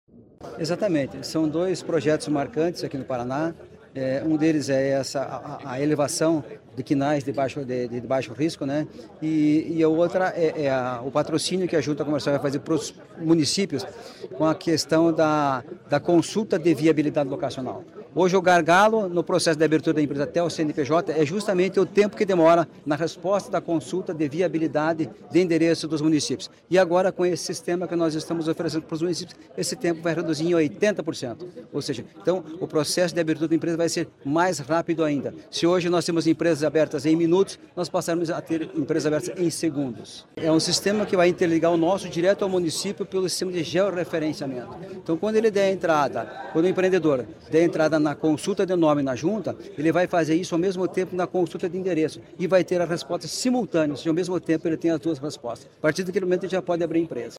Sonora do presidente da Jucepar, Marcos Rigoni, sobre ferramenta de georreferenciamento para acelerar abertura de empresas